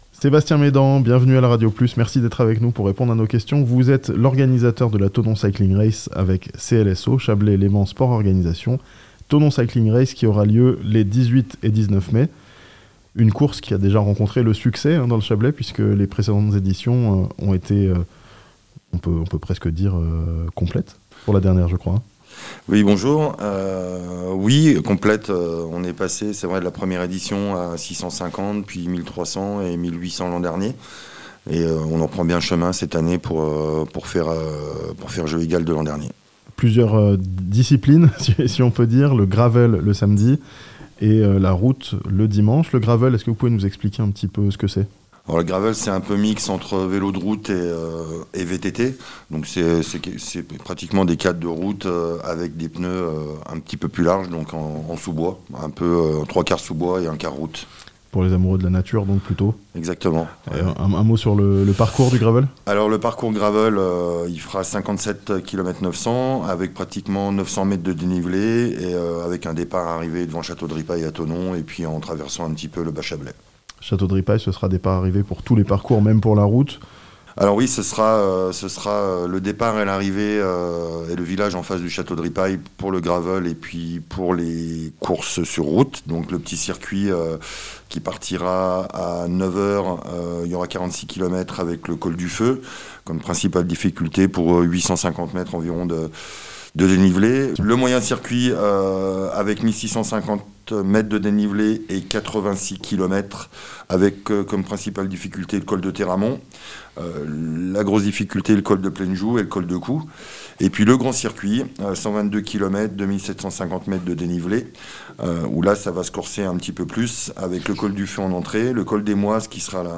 Des milliers de cyclistes attendus dans le Chablais pour la Thonon Cycling Race les 18 et 19 mai (interview)